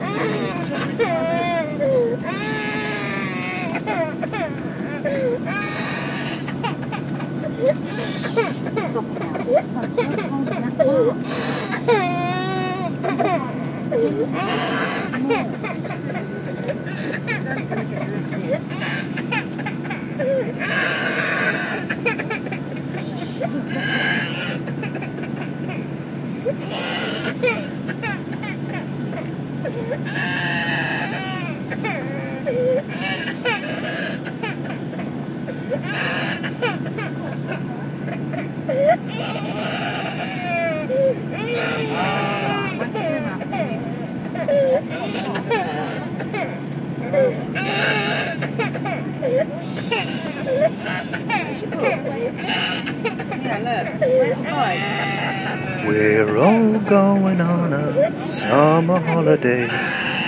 The ambient sounds of flying Ryan Air